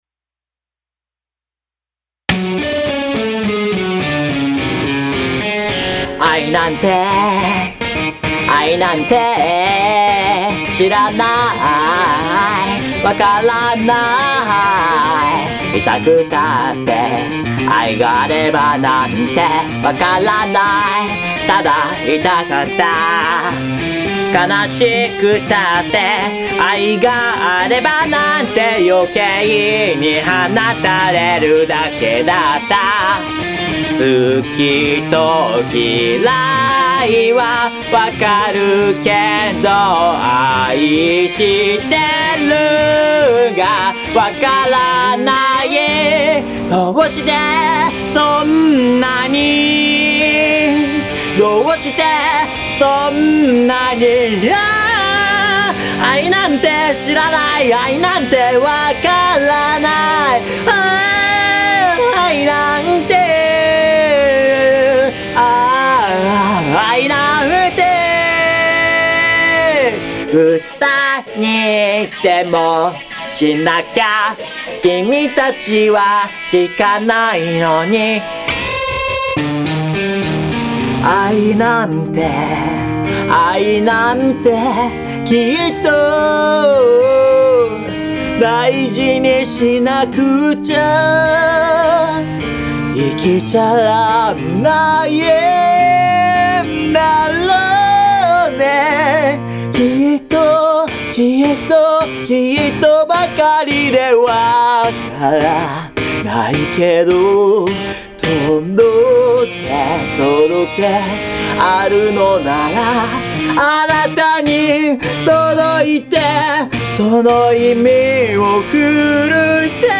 打ち込みと歌録りで曲にしてみました。
↓原作通りのボーカルとギターのみのバージョン。
変拍子にしてしまいました。
フラットが出まくる偏屈なマイナーメロディにしました。